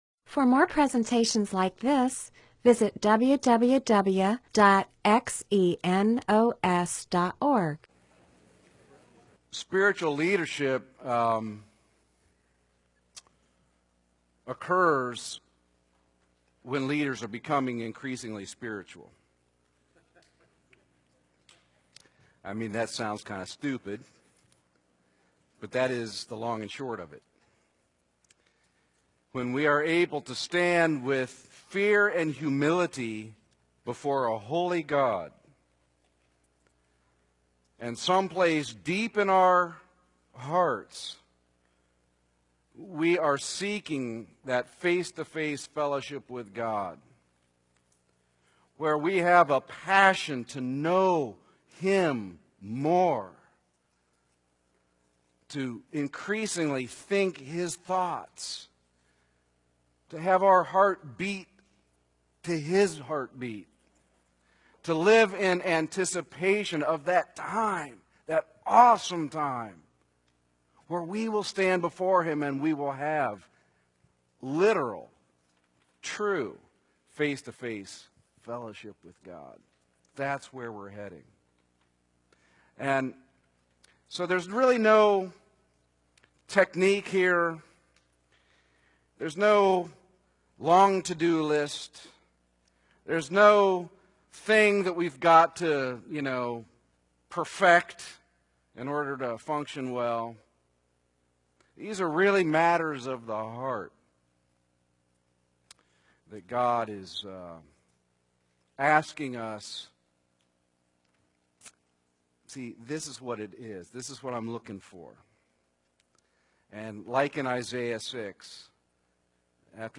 Bible teaching (presentation, sermon) on Exodus 3:10-4:20, Title: Who is Adequate For These Things?